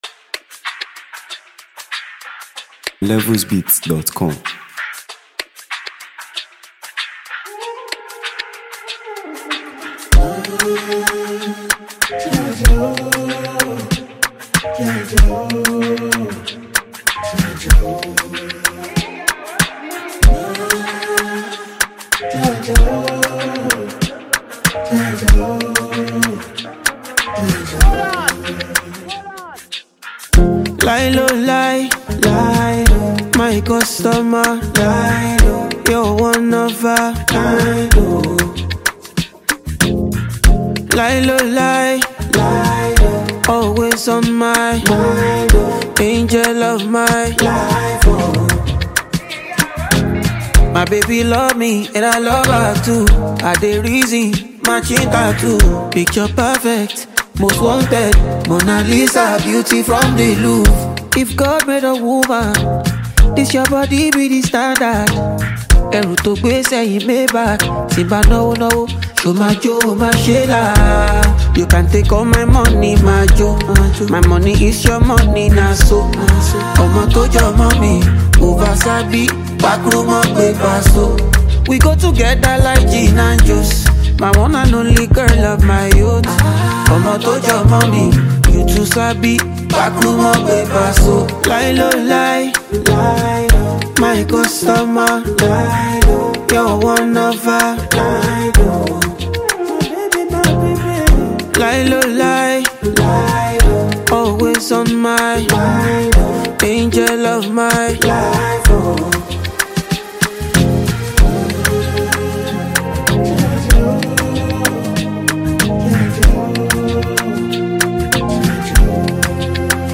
creating a vibe that is both engaging and memorable.